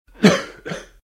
cough5.ogg